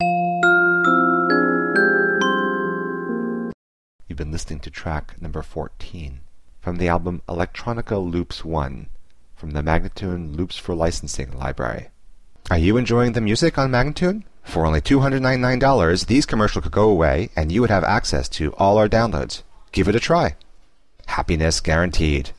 140-C-ambient:teknology-1035